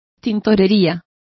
Also find out how tintoreria is pronounced correctly.